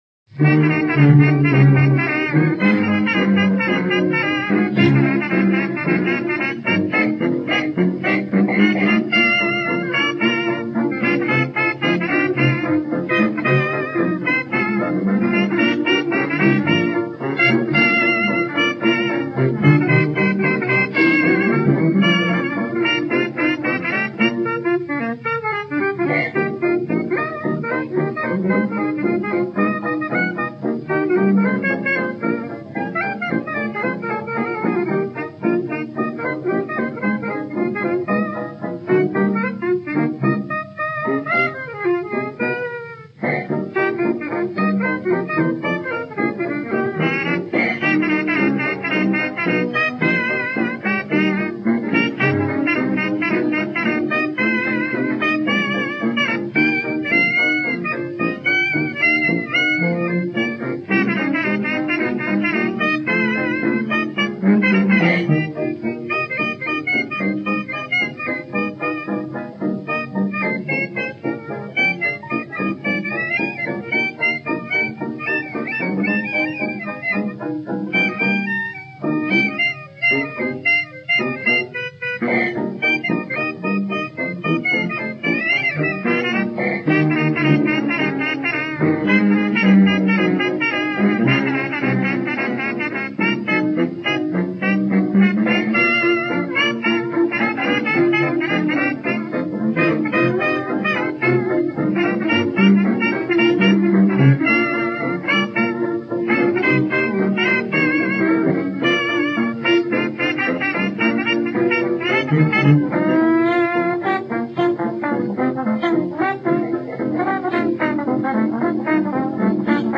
Unknown orchestra, unknown song, late 1920s jazz.